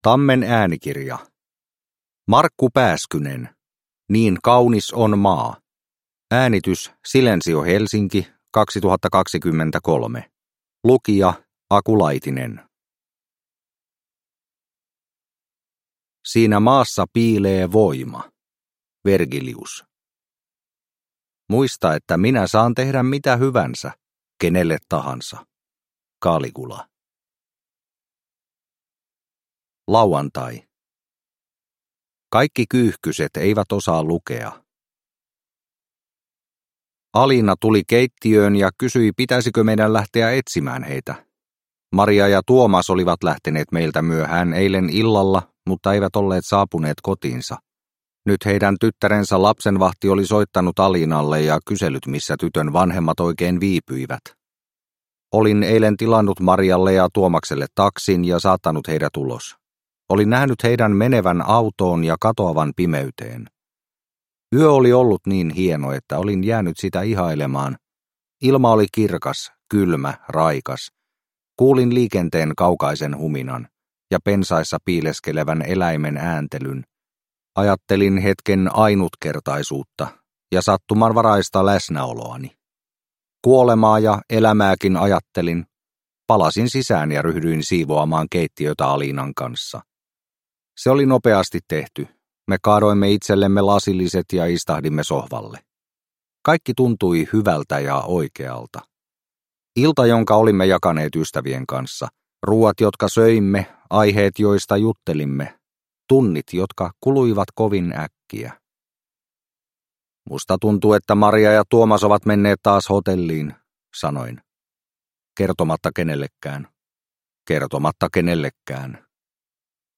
Niin kaunis on maa – Ljudbok – Laddas ner